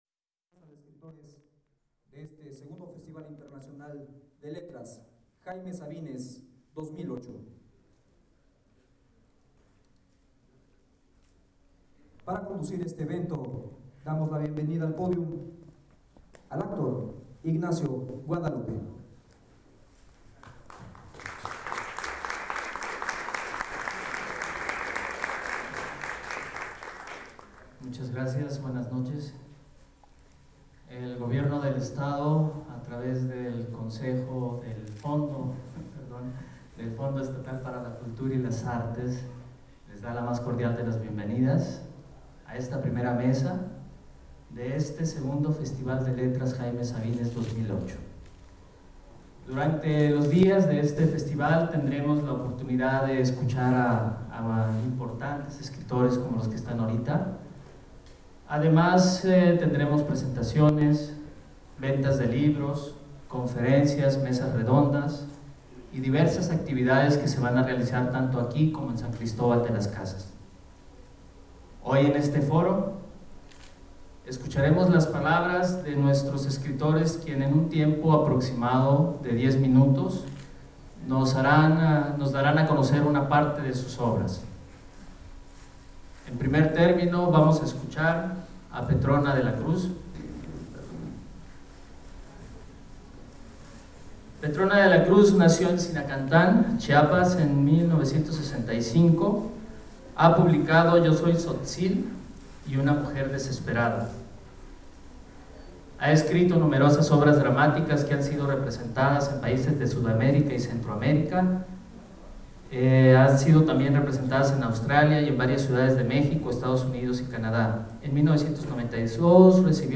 Lugar: Auditorio del Centro Cultural de Chiapas Jaime Sabines, Tuxtla Gutierrez, Chiapas. Mexico Equipo: iPod 2Gb con iTalk Fecha: 2008-10-29 08:20:00 Regresar al índice principal | Acerca de Archivosonoro